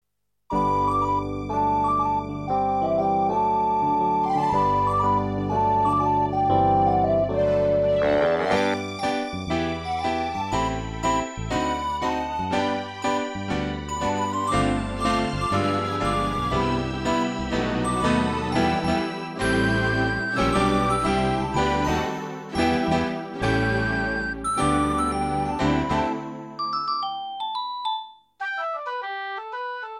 V posledních letech Rudolf Křesťan své fejetony sám čte za rozhlasovým mikrofonem. Pro své autorské čtení v nahrávacím studiu si vybral 33 nejúspěšnějších fejetonů ze své dosavadní tvorby.